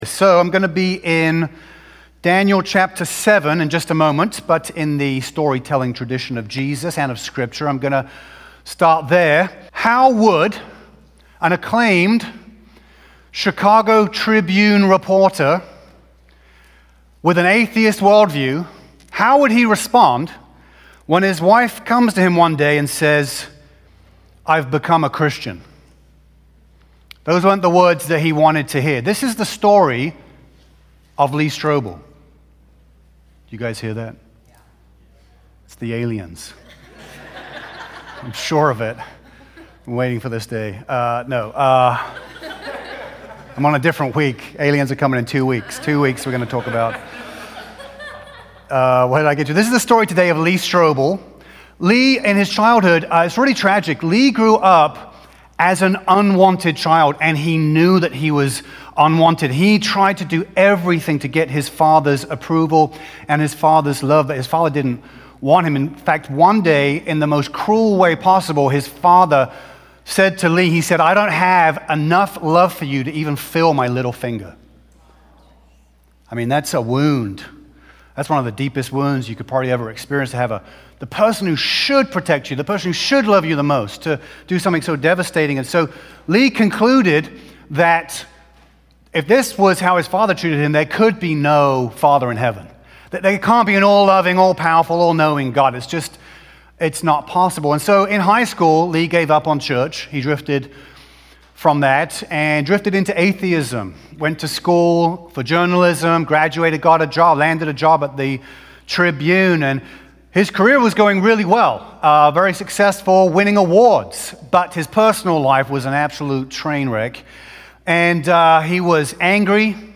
A message from the series "Bible Revival."